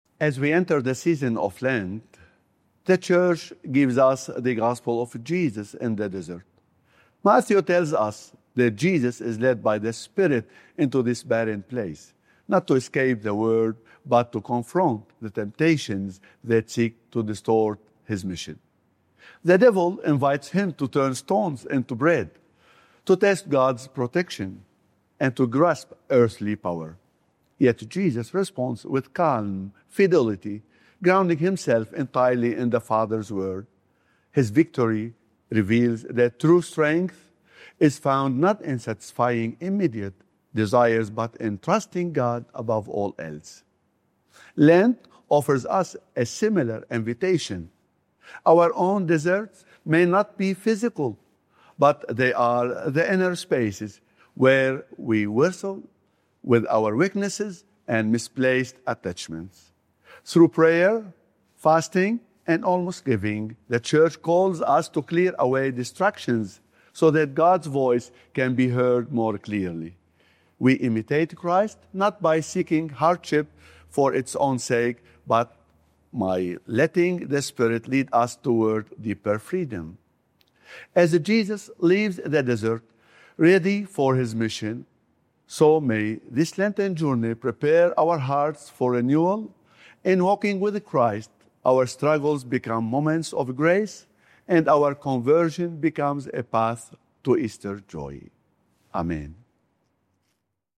Archdiocese of Brisbane First Sunday of Lent - Two-Minute Homily